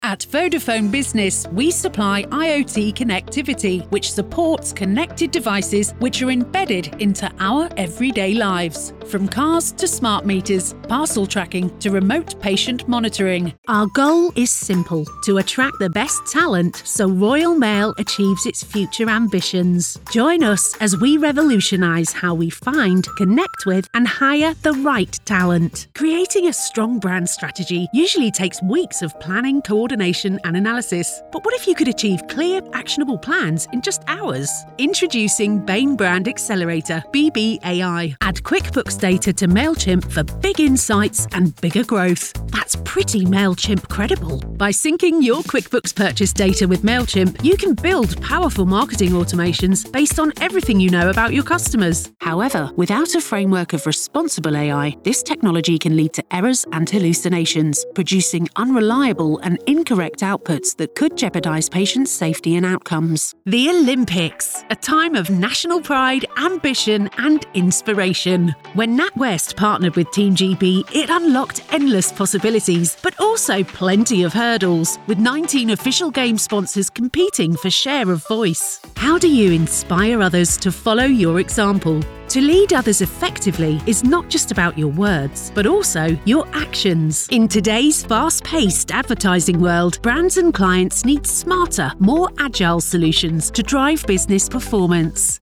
From warm, smooth and classy to fresh, upbeat and natural.
Corporate Demo
Middle Aged
I have my own professional home studio and can deliver a fast turnaround between 24-48 hours.